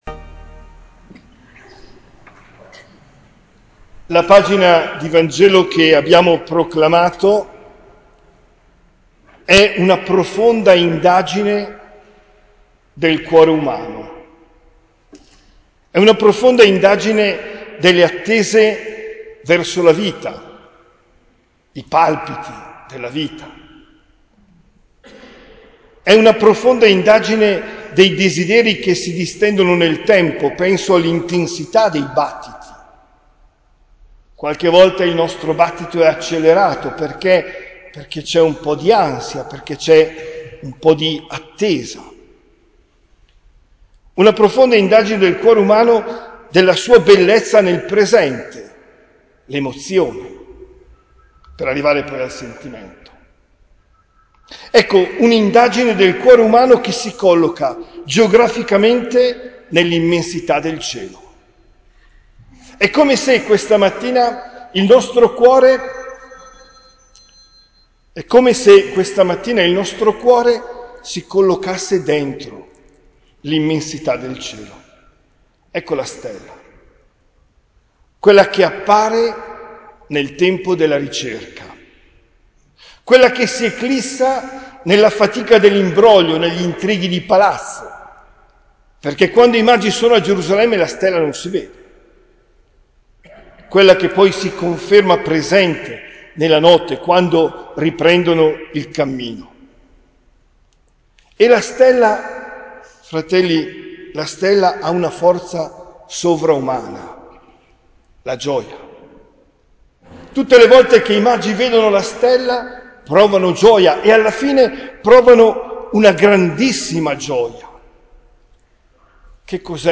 OMELIA DEL 06 GENNAIO 2023